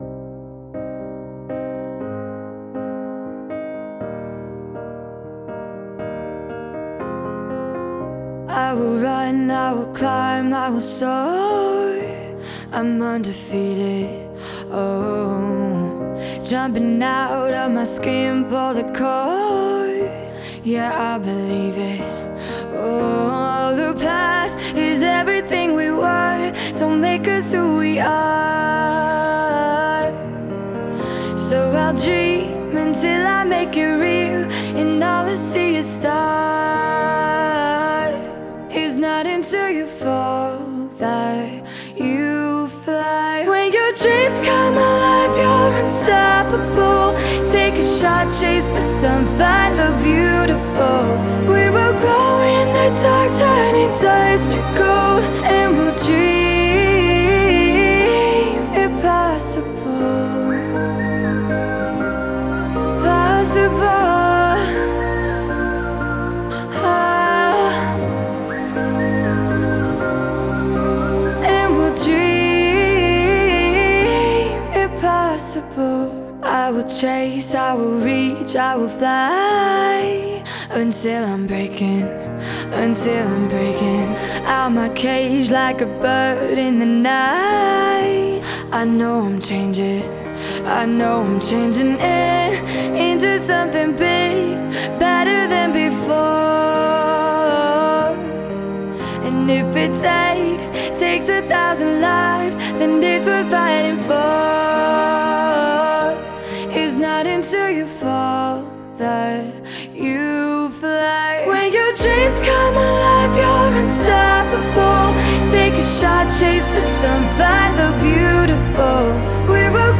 national anthem